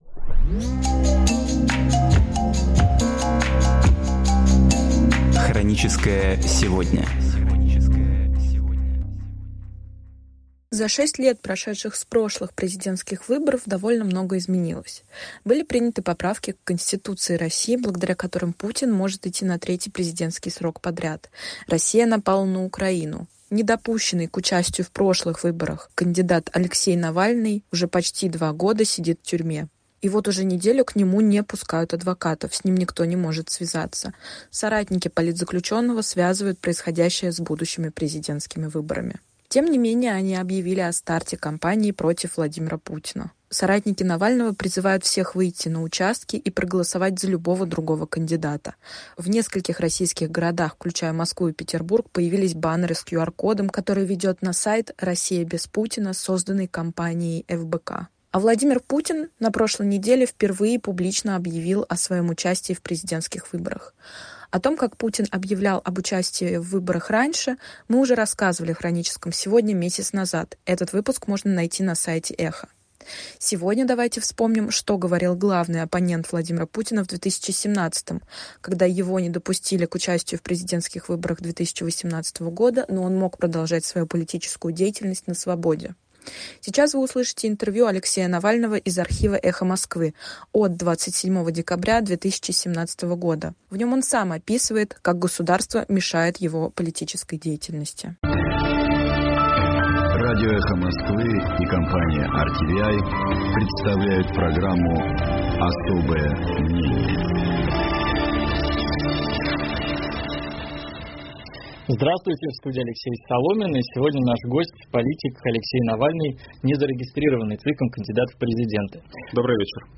Сейчас вы услышите интервью Алексея Навального из архива Эха Москвы от 27 декабря 2017 года. В нем он сам описывает, как государство мешает его политической деятельности.